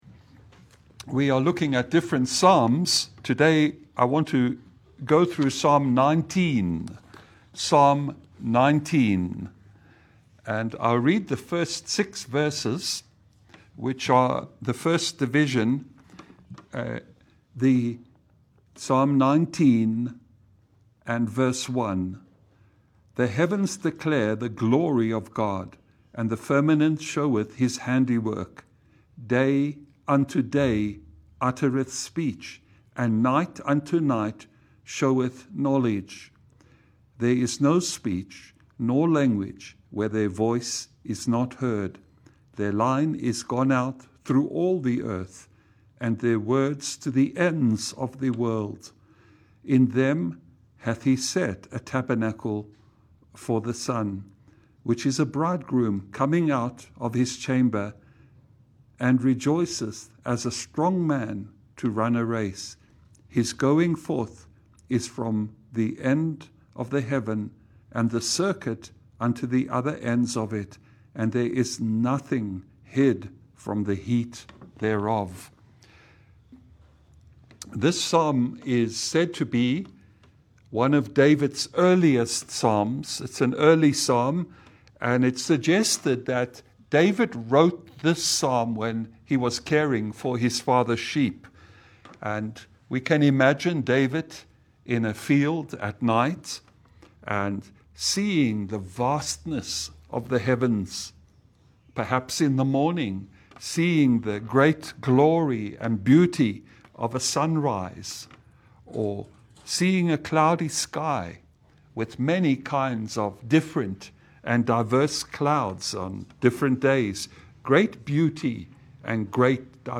Psalm 19 Service Type: Lunch hour Bible Study « Walking in the Spirit The True Mark of a Living Faith